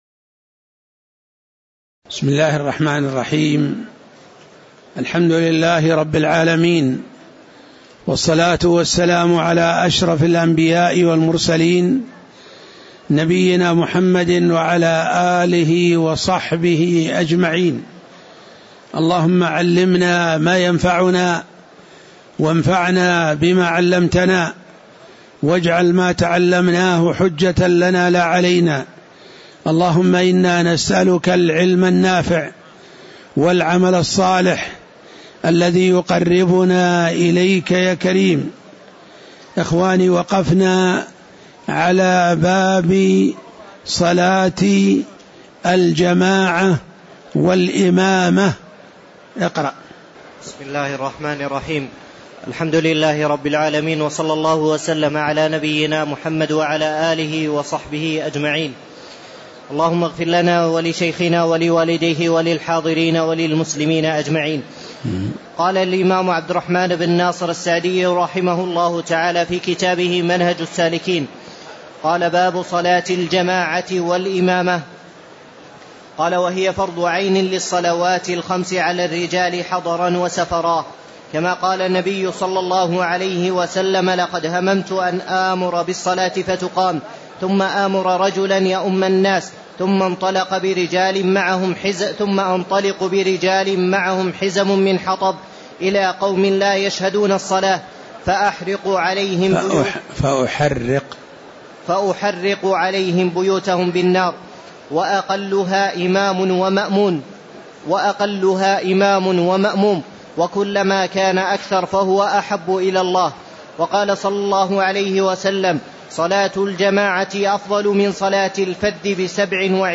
تاريخ النشر ١٥ شوال ١٤٣٧ هـ المكان: المسجد النبوي الشيخ